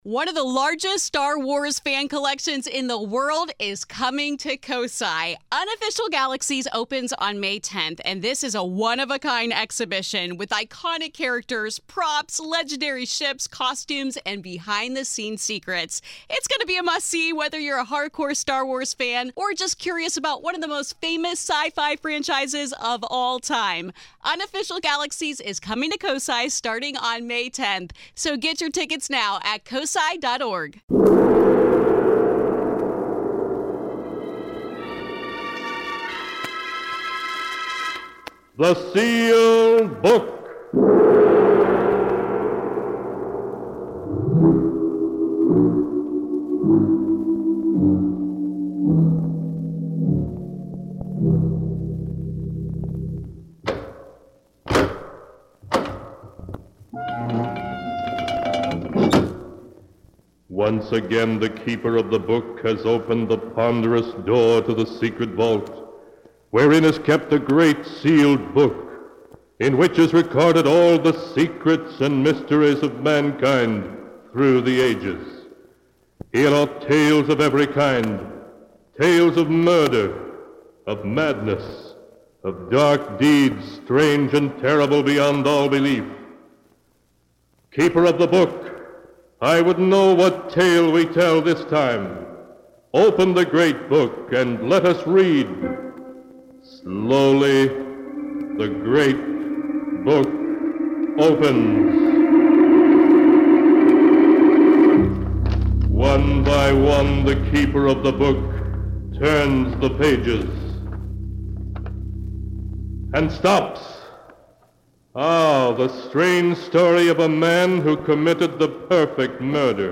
On this week's episode of the Old Time Radiocast, we present you with two stories from the classic radio program The Sealed Book!